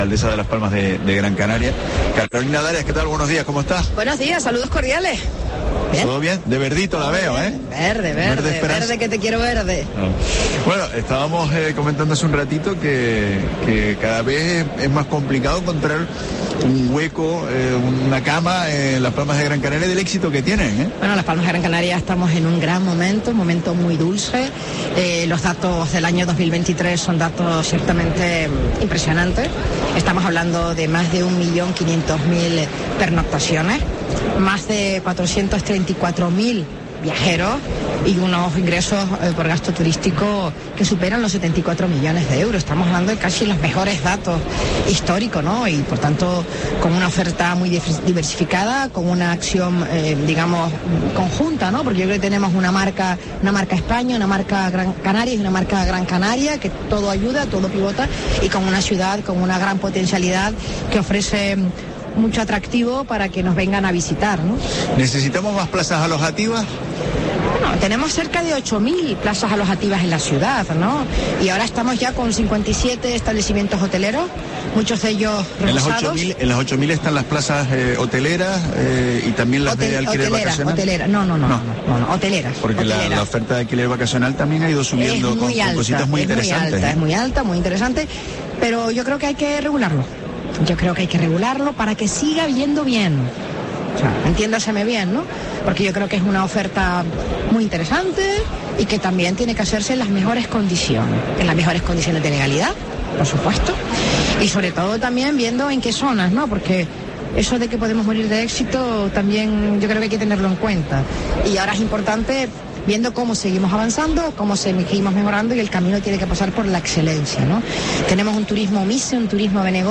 Entrevista a Carolina Darias, alcaldesa de Las Palmas de Gran Canaria